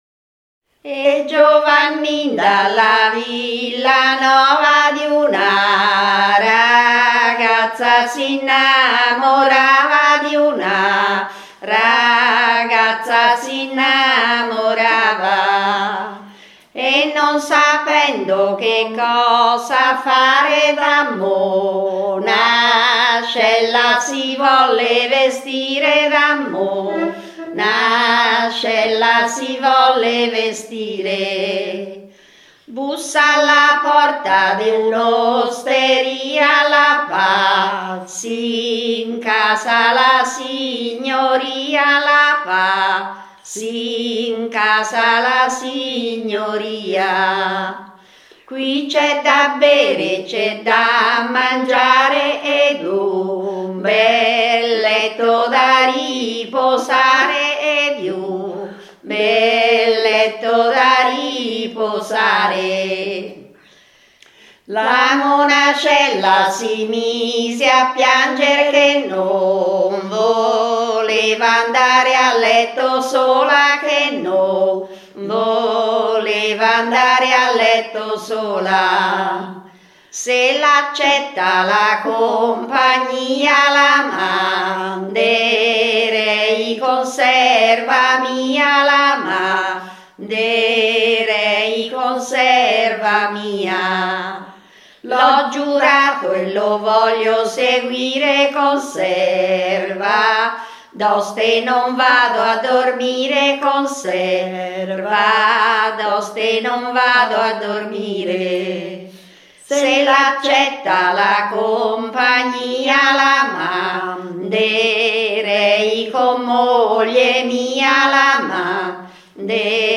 Canti di tradizione orale ricordati e interpretati nel territorio di Pelago
Reg. a Ferrano il 10 marzo 2005